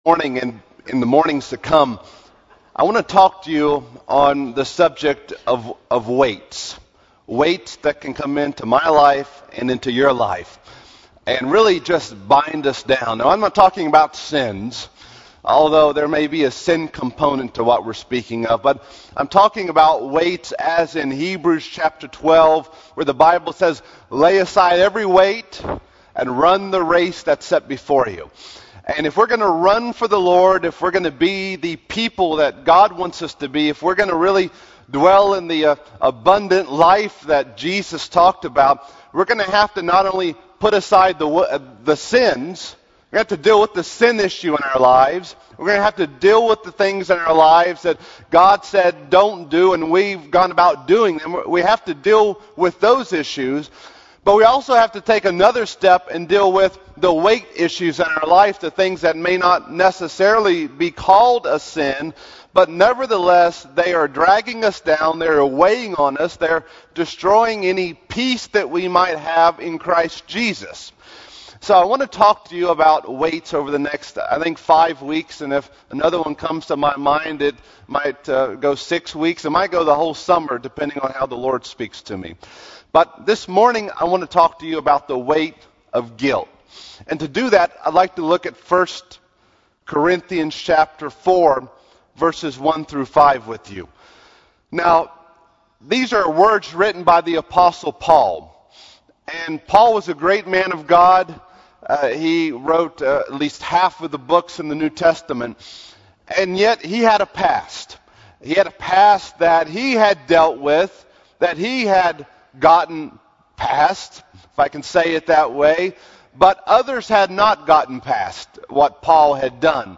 Sermon Podcasts / Message Downloads | First Church of God at Greeneville, Tennessee